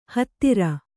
♪ hattira